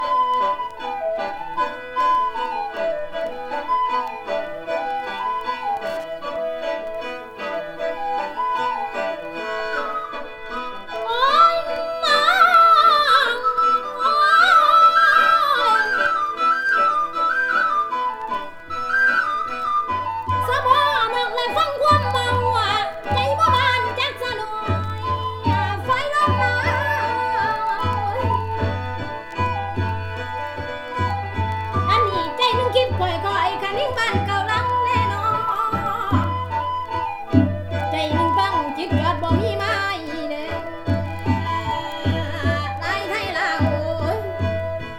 World, Folk, Filed Recording　France　12inchレコード　33rpm　Stereo